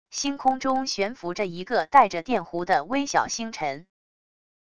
星空中悬浮着一个带着电弧的微小星辰wav音频